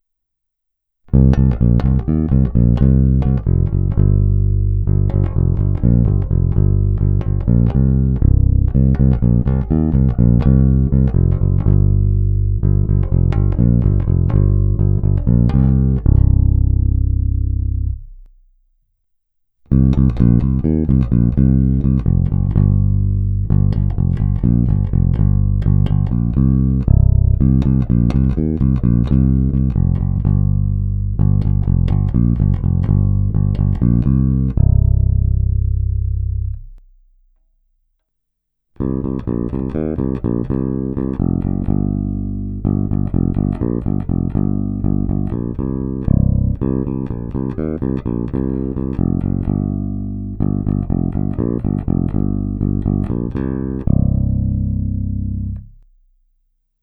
Není-li uvedeno jinak, následující nahrávky jsou provedeny rovnou do zvukové karty, v pasívním režimu a s plně otevřenou tónovou clonou.